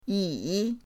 yi3.mp3